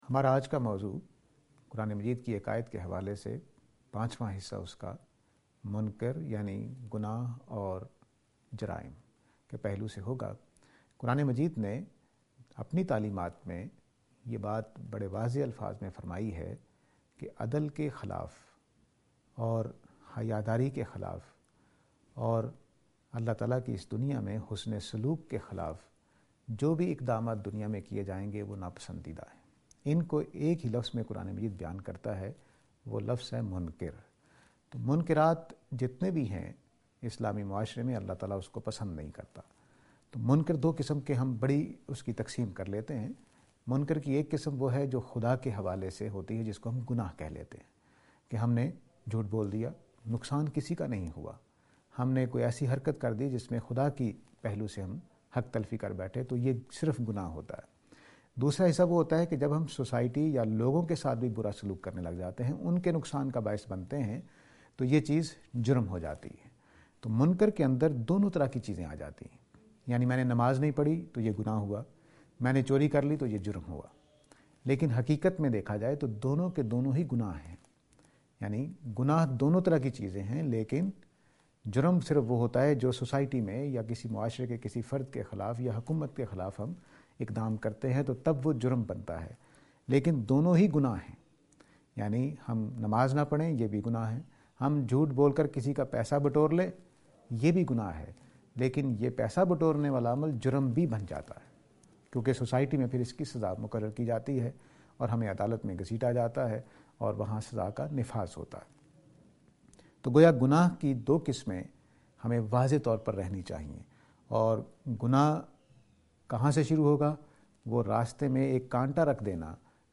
This lecture is and attempt to answer the question "Sin and Crime".